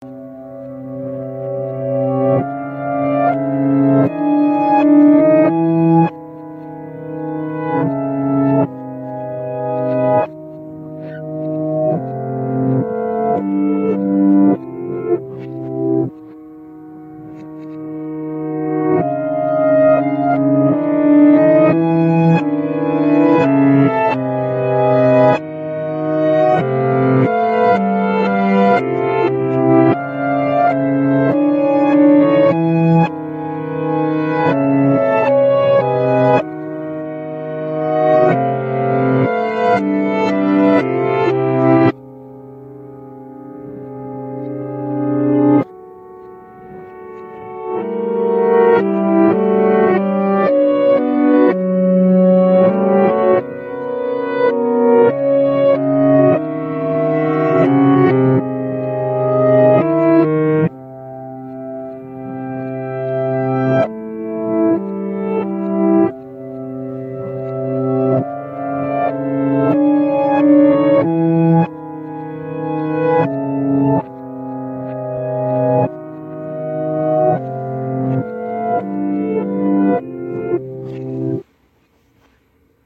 Piano performed by
Recorded at the University of Las Vegas